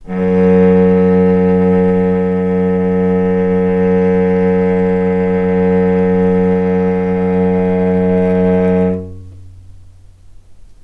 healing-soundscapes/Sound Banks/HSS_OP_Pack/Strings/cello/ord/vc-F#2-mf.AIF at 48f255e0b41e8171d9280be2389d1ef0a439d660
vc-F#2-mf.AIF